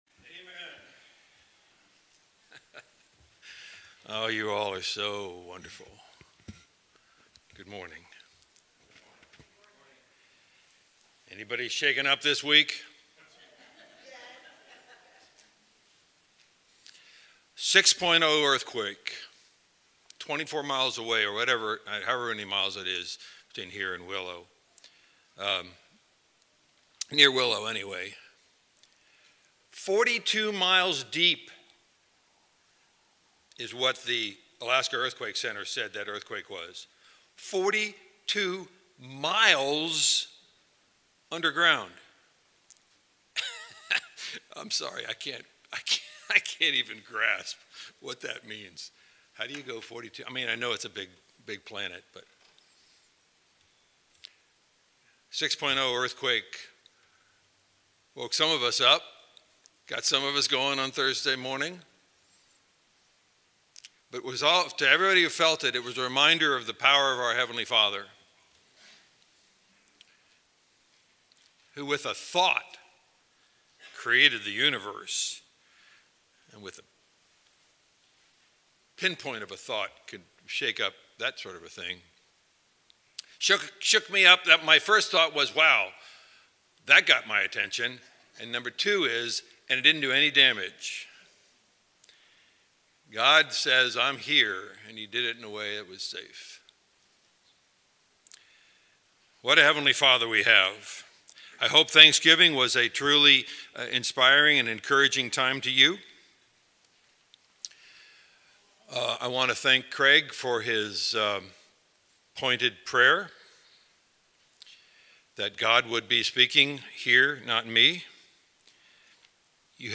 Valley church of Christ - Matanuska-Susitna Valley Alaska
Sermons